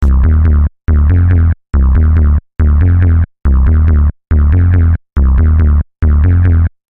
标签： 140 bpm Electronic Loops Bass Loops 1.15 MB wav Key : Unknown
声道立体声